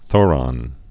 (thôrŏn)